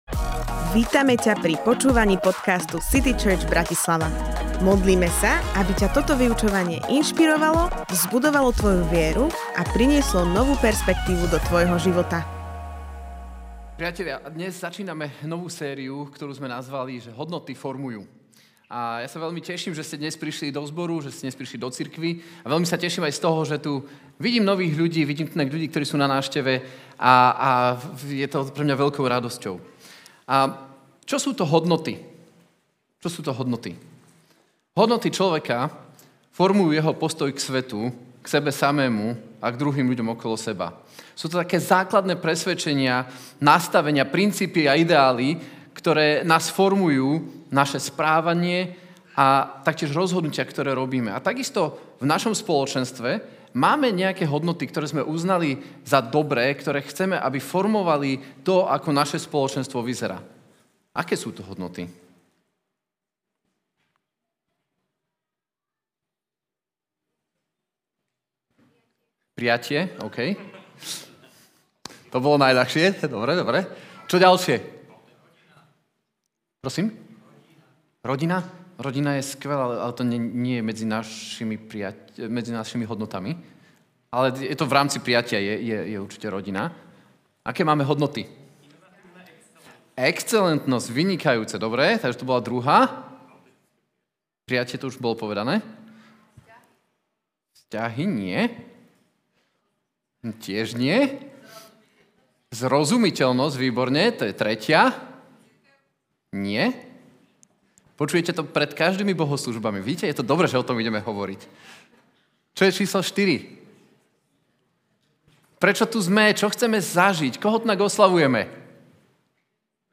Prijatie Kázeň týždňa Zo série kázní